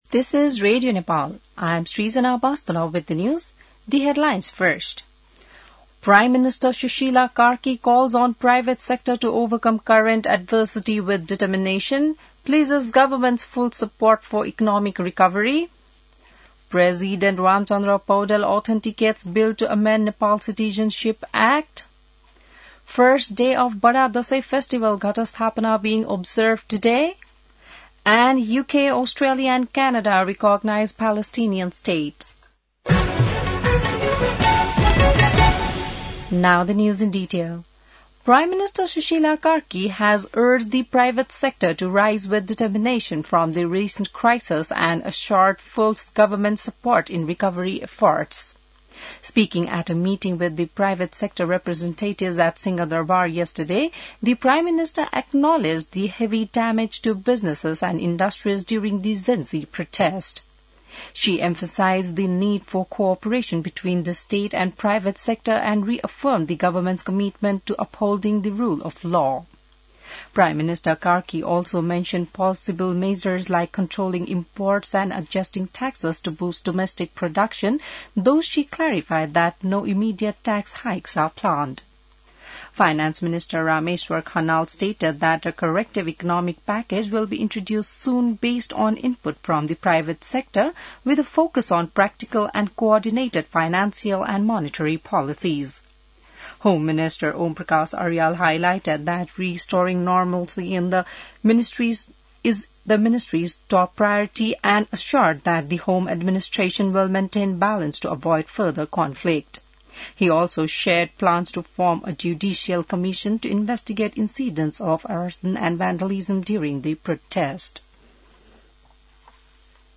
An online outlet of Nepal's national radio broadcaster
बिहान ८ बजेको अङ्ग्रेजी समाचार : ६ असोज , २०८२